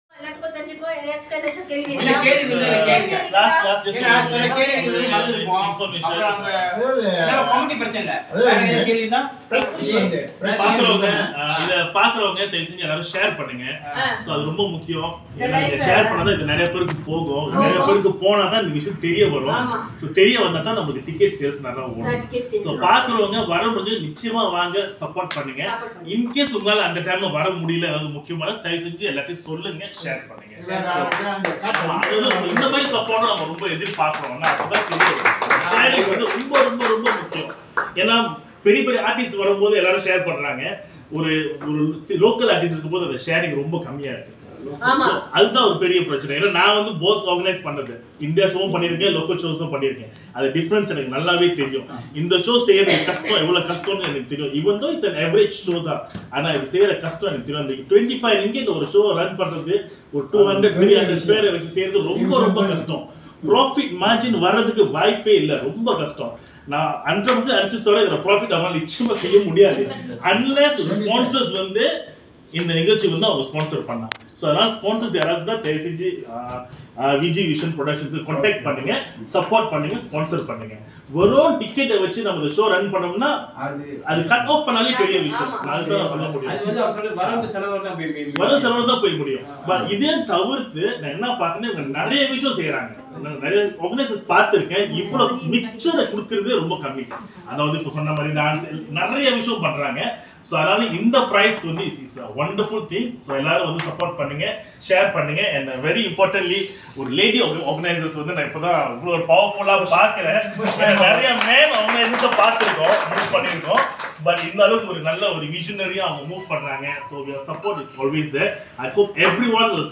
Co Chaired a Press Conference sound effects free download